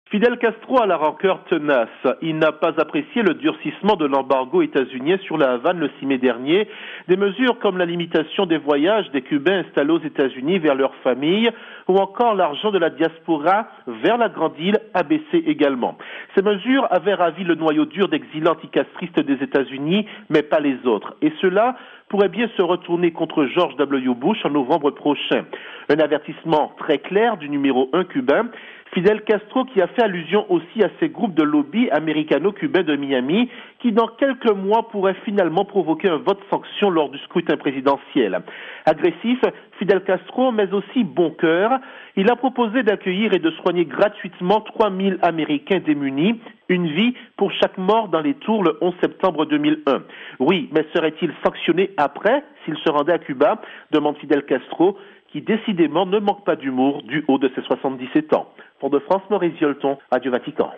Correspondance régionale